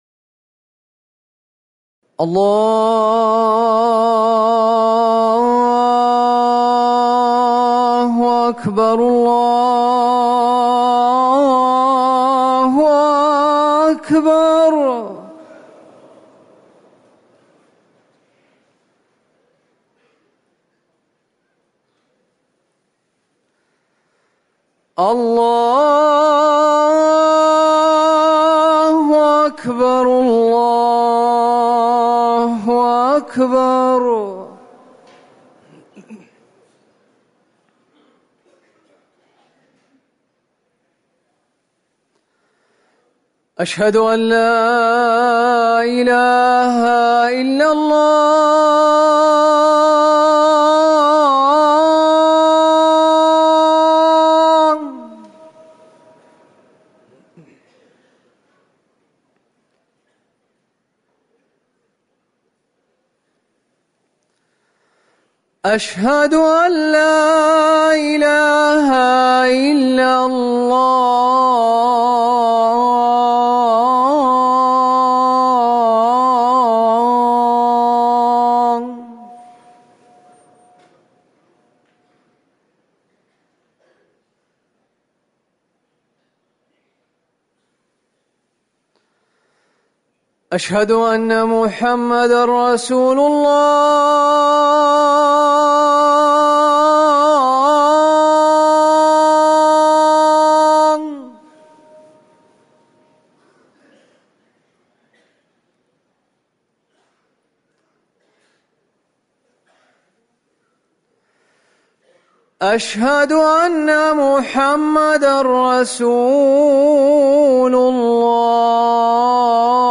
أذان العصر
تاريخ النشر ٢٢ صفر ١٤٤١ هـ المكان: المسجد النبوي الشيخ